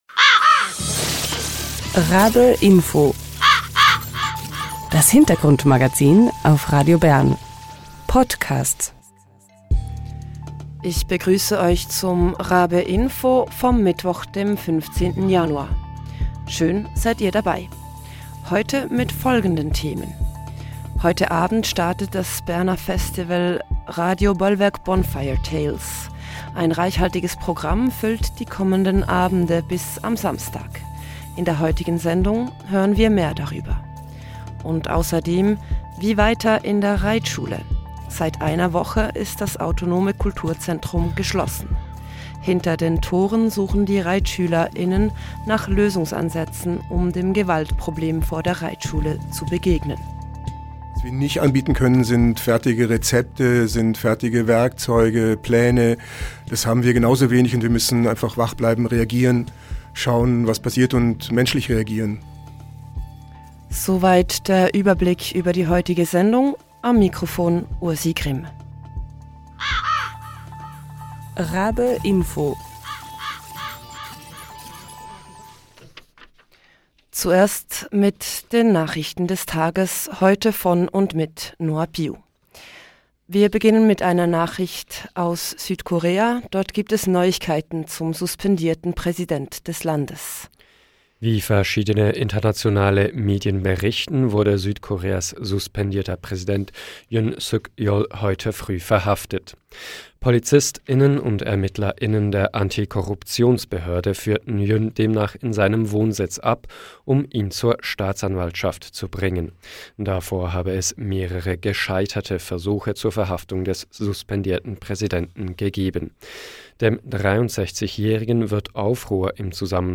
Wir hören warum es dieses Festival braucht und was es dort zu hören gibt.